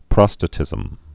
(prŏstə-tĭzəm)